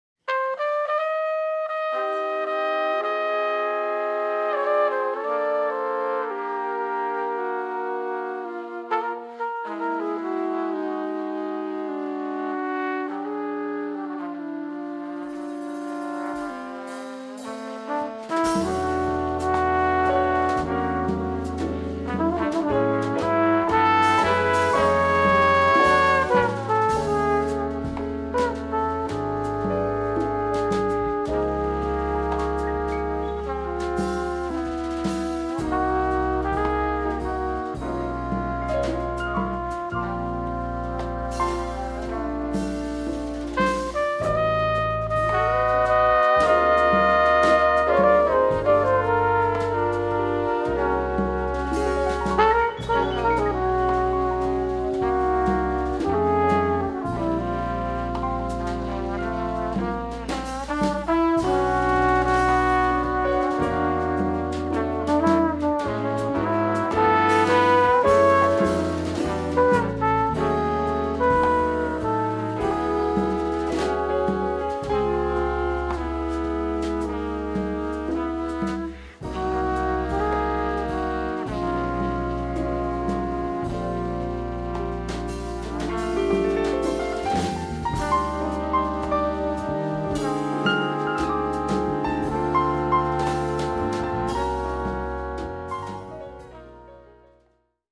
trombone
piano
tenor saxophone
alto saxophone & flute
trumpet & flugelhorn
bass
drums
congas
Afro-Cuban and Afro-Caribbean rhythms